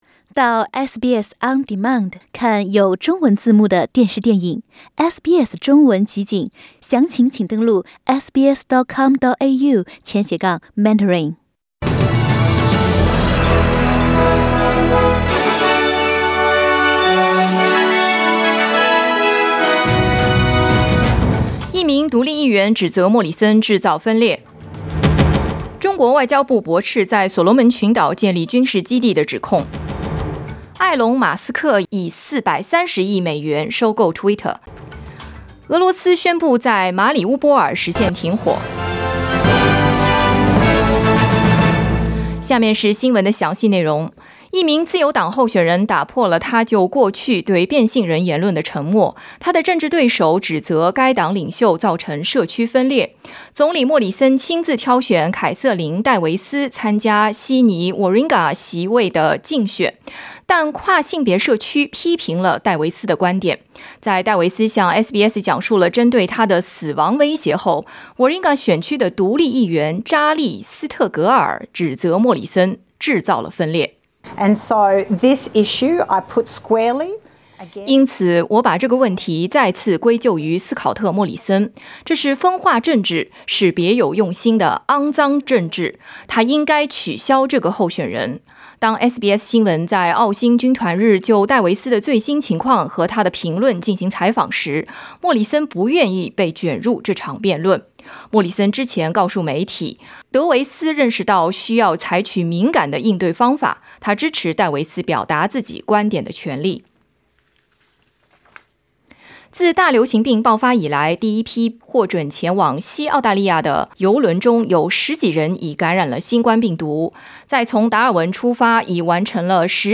SBS早新闻 (2022年4月26日)
SBS Mandarin morning news Source: Getty Images